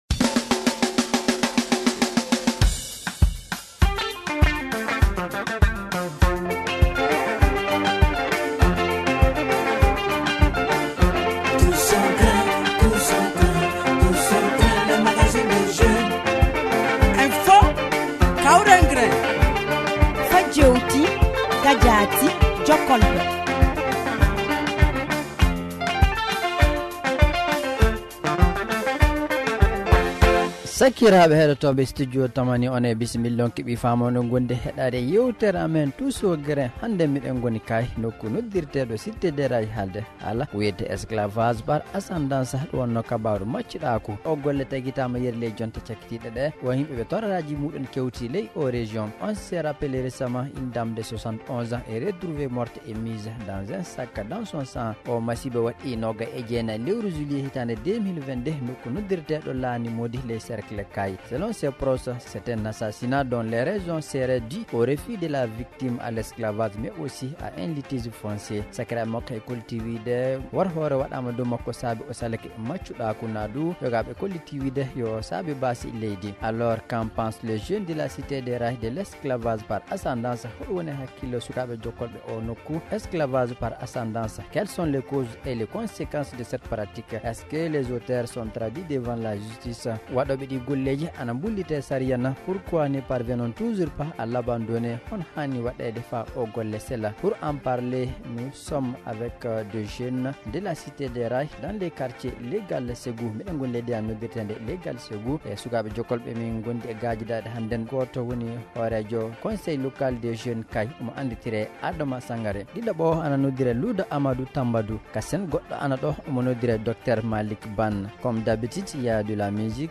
L’équipe « Tous au Grin » était cette semaine à Kayes pour parler de l’esclavage par ascendance.